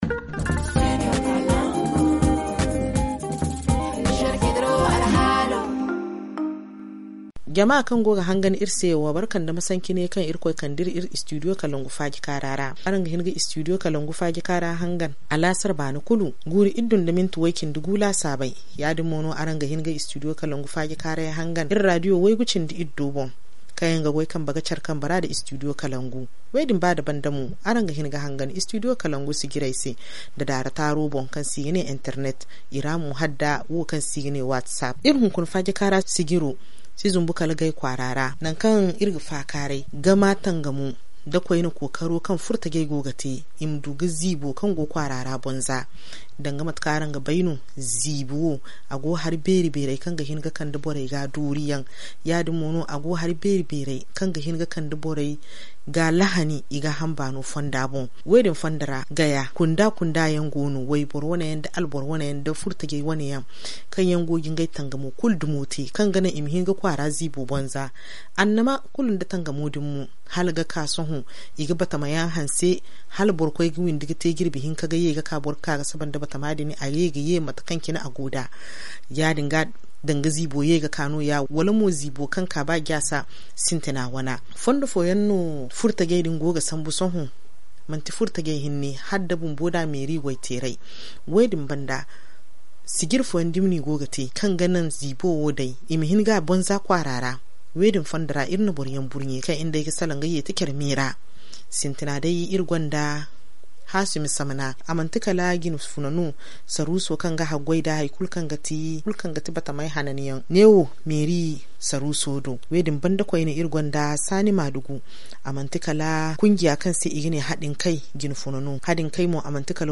ZA Le forum en Zarma Télécharger le forum ici.